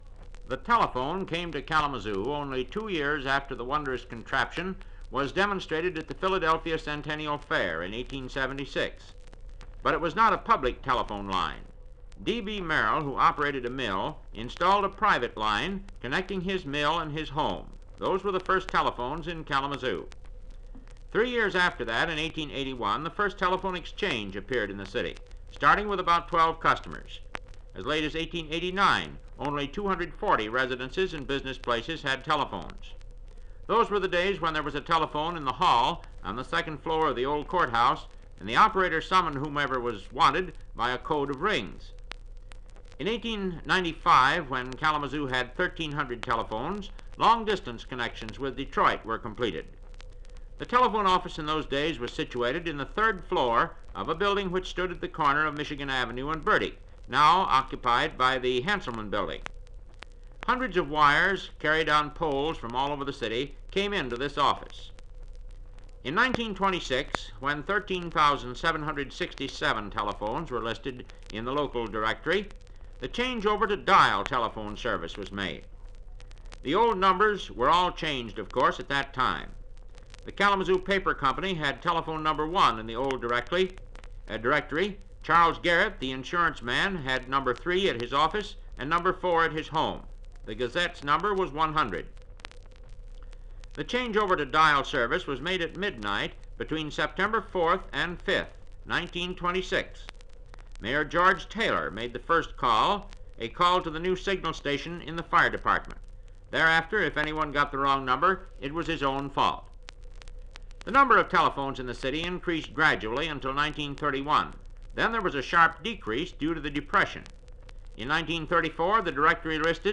Radio programs
Broadcast 1950 May 30